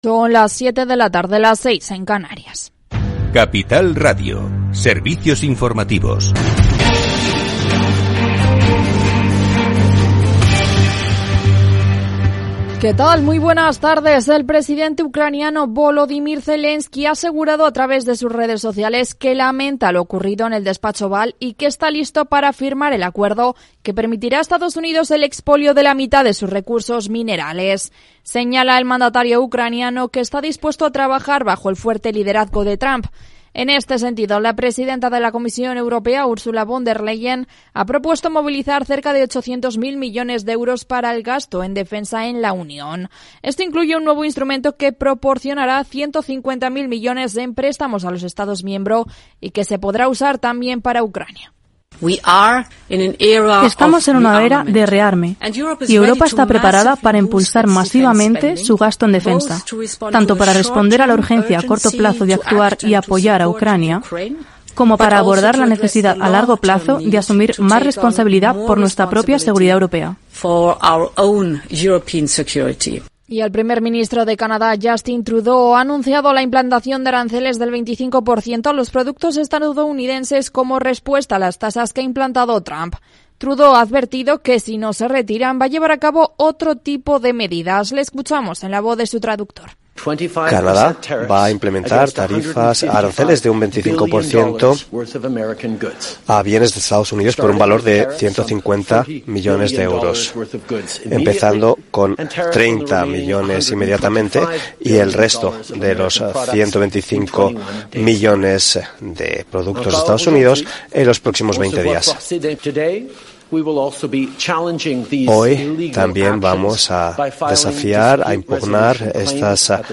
Programa diario dedicado a las pymes, profesionales, autónomos y emprendedores. Hablamos de Big Data y de crowdfunding, de management y coaching, de exportar e importar, de pedir créditos a los bancos y de empresas fintech. Los especialistas comparten sus experiencias, sus casos de éxito y sus fracasos. Todo en un tono propio de un Afterwork, en el que podrás hacer un poco de networking y hacer negocios más allá de la oficina.